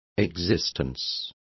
Complete with pronunciation of the translation of existences.